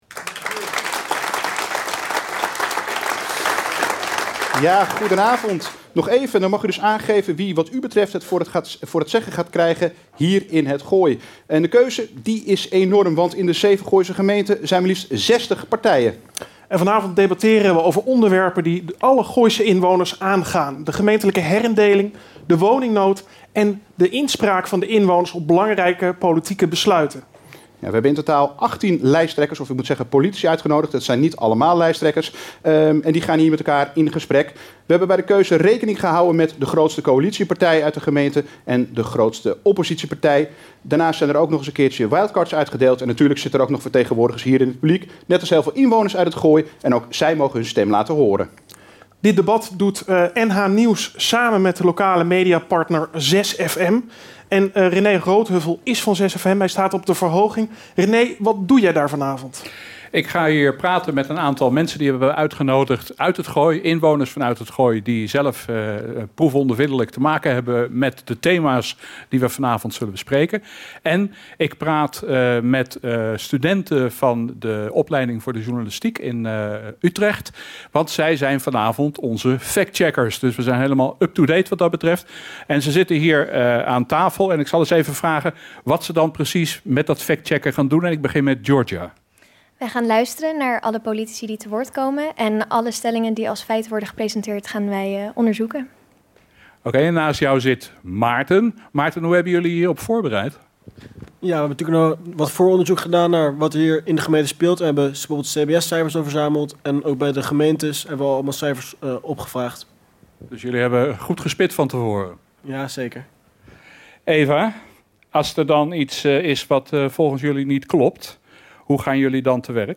In De Vorstin in Hilversum werd dinsdag 13 maart het NH Gooi debat gehouden.
Zeven gemeenten en achttien deelnemers debatteerden over drie stellingen.
Politiek Café - NH Gooi debat vanuit De Vorstin in Hilversum